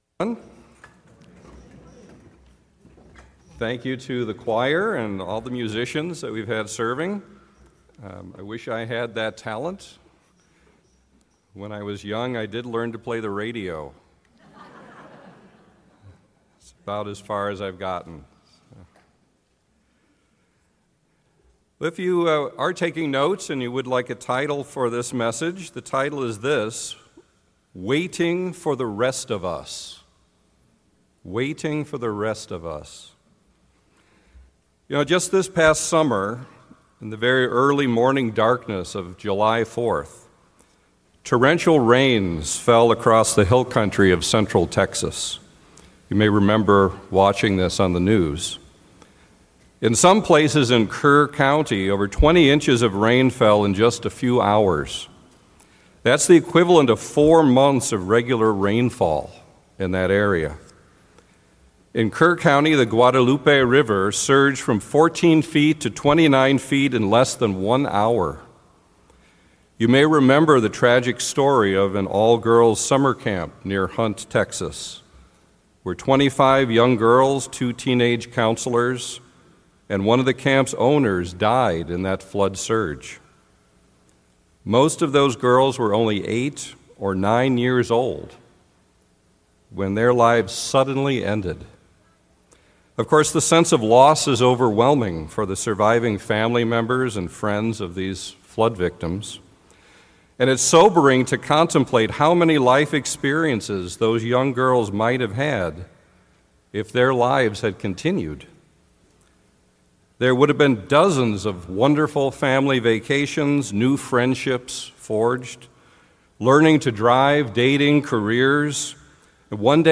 The sermon will emphasize that, although the White Throne Judgment does involve judgment (conviction of sins), it is also a resurrection to mercy and reconciliation, extended by God to all the branches of the human family (including those of the Houses of Israel and Judah who perished prior to Christ’s return).